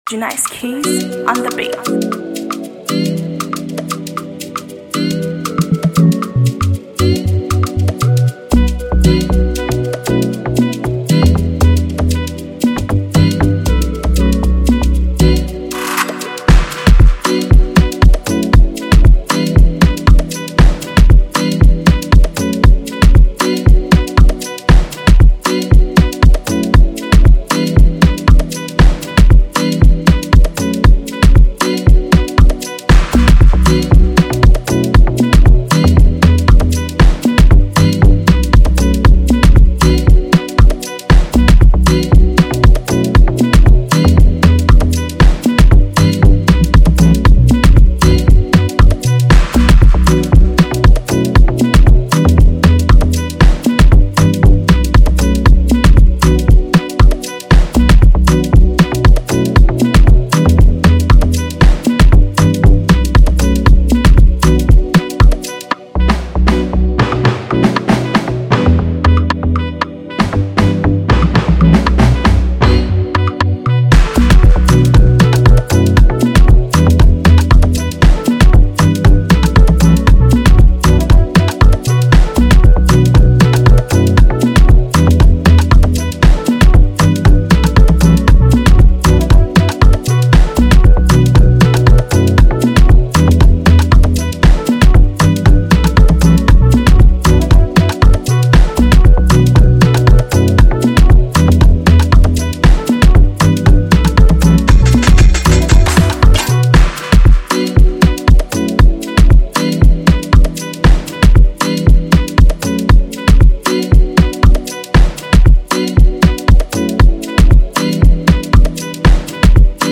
Instrumental free beat